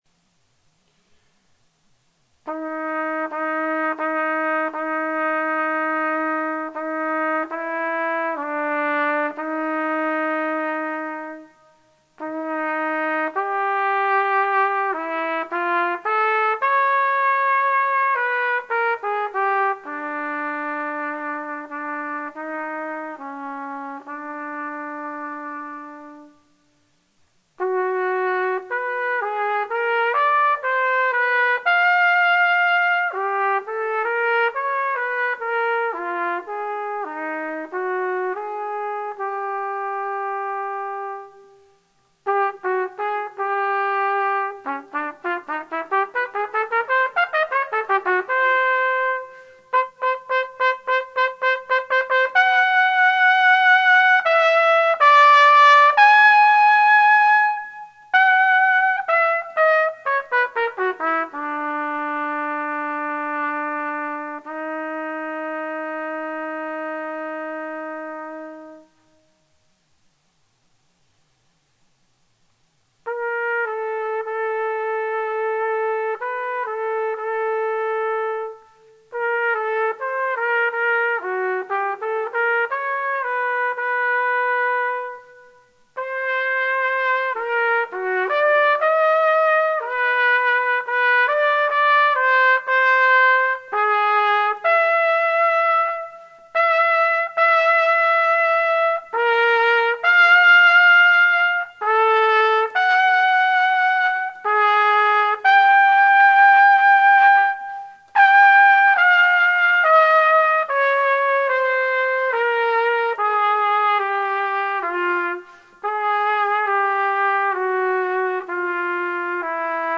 Solo Trumpet
Willwerth Essays for Unaccompanied Trumpet